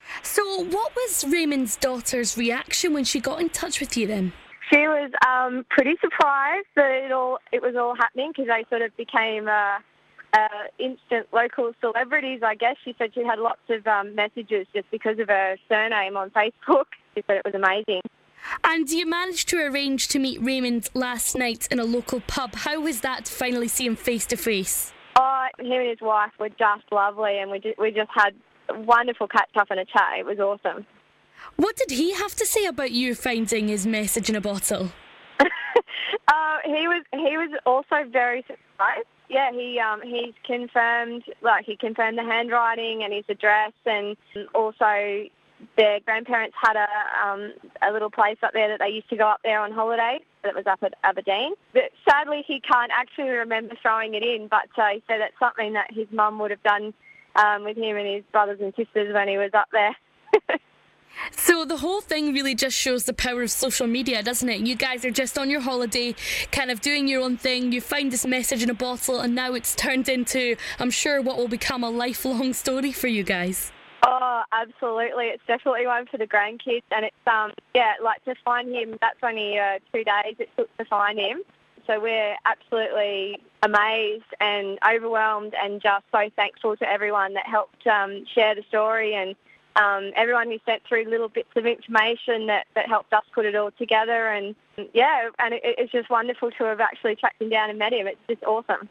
LISTEN: Couple Describe Handing Man Back His 1971 Message In A Bottle They Found On Peterhead Beach